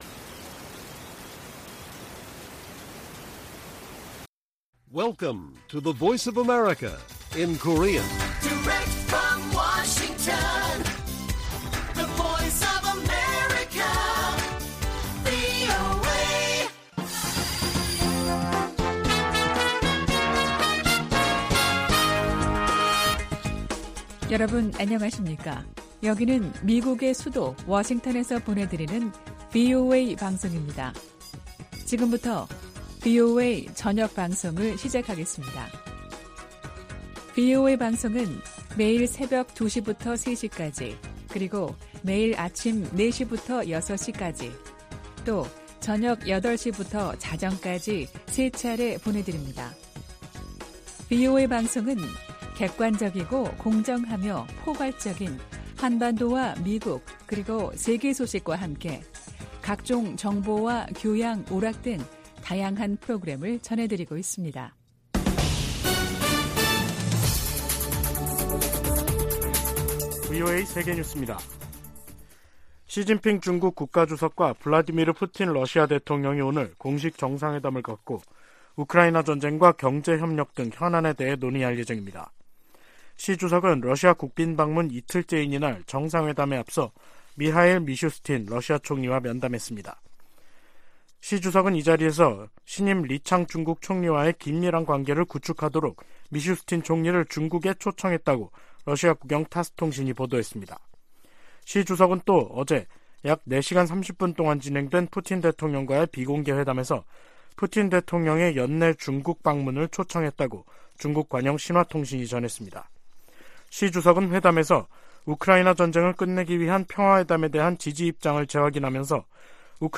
VOA 한국어 간판 뉴스 프로그램 '뉴스 투데이', 2023년 3월 21일 1부 방송입니다. 북한이 모의 핵탄두를 탑재한 미사일 공중폭발 시험훈련에 성공했다고 밝히면서 전술핵 위협이 한층 현실화했다는 평가가 나옵니다. 유엔 안전보장이사회가 북한의 대륙간탄도미사일(ICBM) 발사에 대응한 공개회의를 개최하고 북한을 규탄했습니다. 북한에서 살인과 고문, 인신매매 등 광범위한 인권 유린 행위가 여전히 자행되고 있다고 미 국무부가 밝혔습니다.